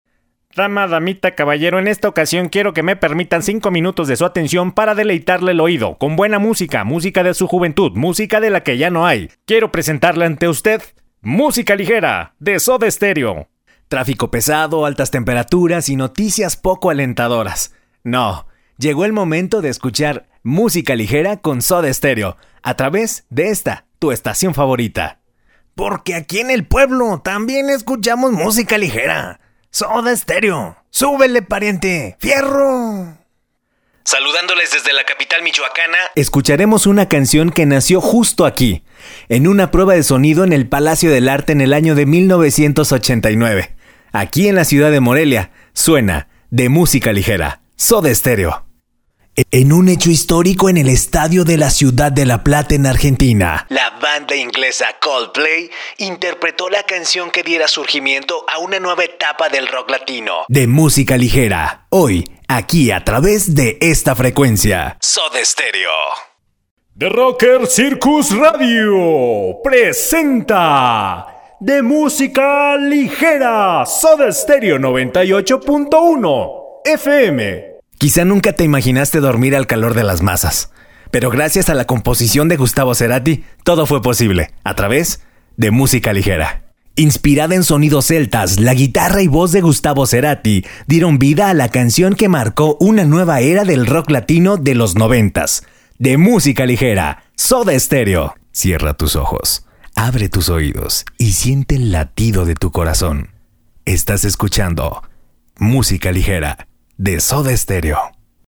Gracias al tono que manejo (voz media), puedo ofrecer diferentes estilos y tonos para darle calidad a tu proyecto.
Sprechprobe: Sonstiges (Muttersprache):